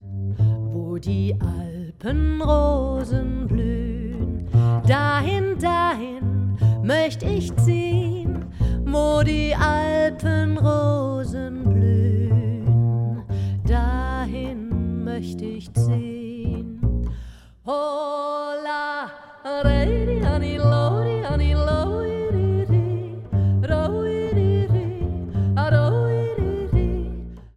Bass